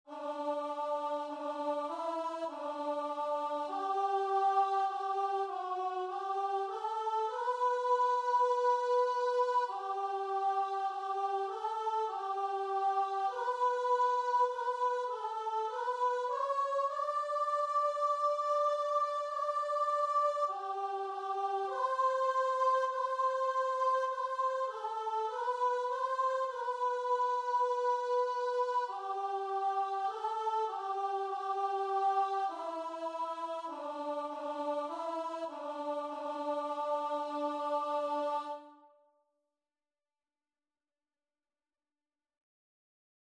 Christian
2/2 (View more 2/2 Music)
Classical (View more Classical Guitar and Vocal Music)